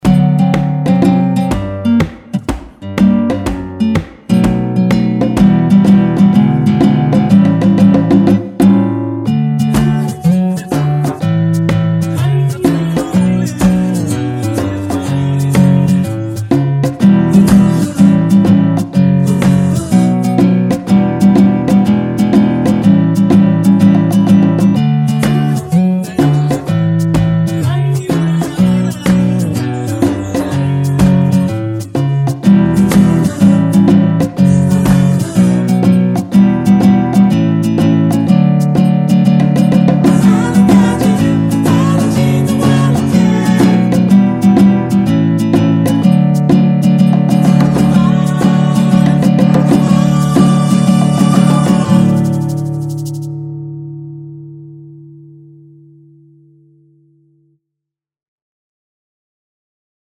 전주 없이 시작 하는곡이라 노래 하시기 편하게 전주 2마디 많들어 놓았습니다.(일반 MR 미리듣기 확인)
원키에서(-2)내린 코러스 포함된 MR입니다.
앞부분30초, 뒷부분30초씩 편집해서 올려 드리고 있습니다.